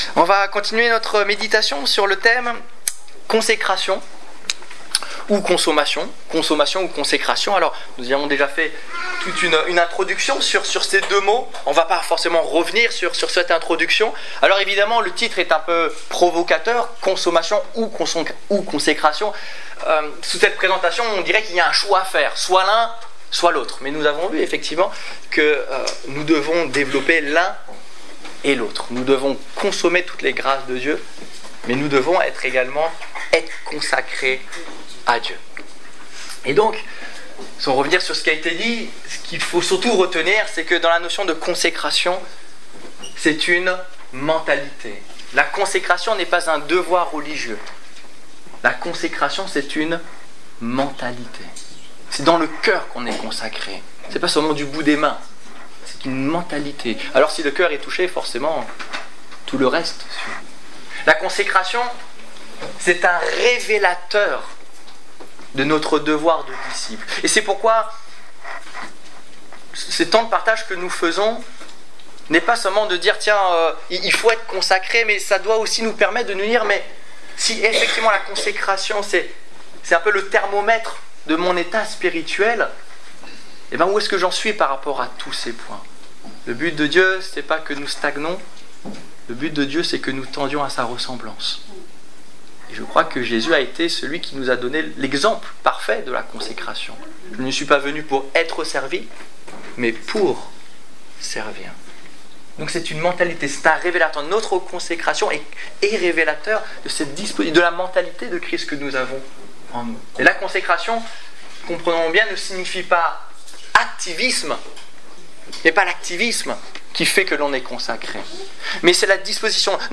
- La Parole de Dieu Détails Prédications - liste complète Culte du 31 mai 2015 Ecoutez l'enregistrement de ce message à l'aide du lecteur